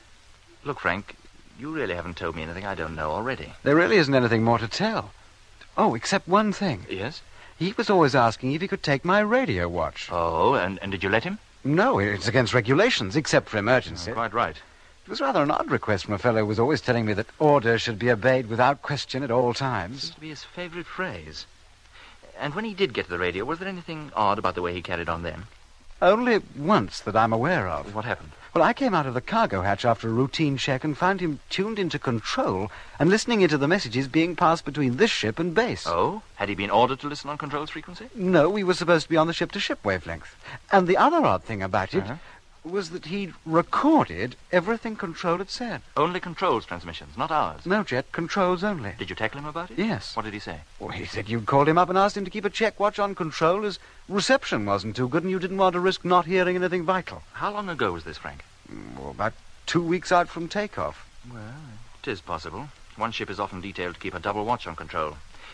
Nadat het vlaggenschip een vreemd bevel heeft ontvangen, besluit Jet/Jeff om Frank Rogers te bevragen over het gedrag van Whitaker. In de Britse versie wordt specifiek benadrukt dat Whitaker alleen de stem van de controle heeft opgenomen.